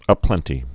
(ə-plĕntē)